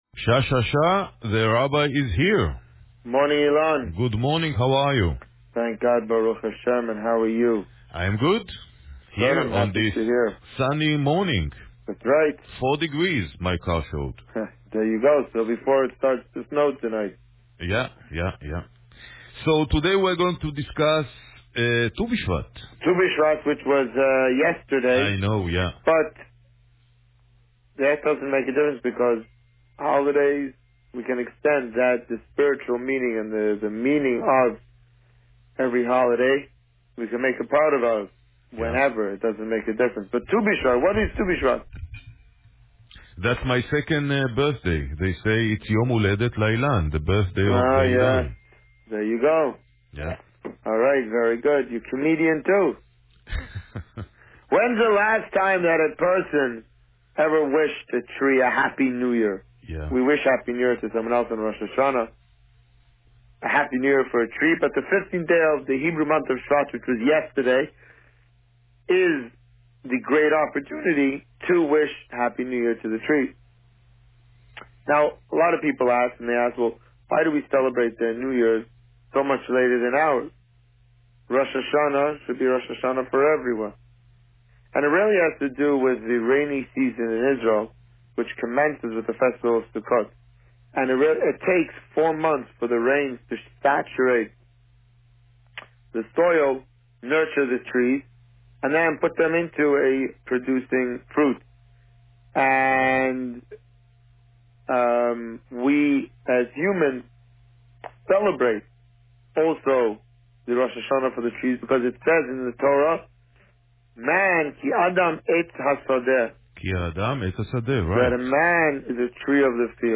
This week, the Rabbi spoke about Tu B'Shevat and hinted at the plans for the upcoming Purim festival. Listen to the interview here.